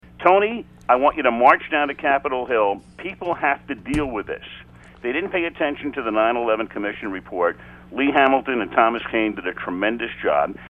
This first item comes from a podcast of The Tony Kornheiser show on December 30, 2009.  Tony and Al Michaels were discussing the difficulty UCLA students had getting to Washington D.C. for a football bowl game in the aftermath of the attempt to bring down a Northwest Airlines plane.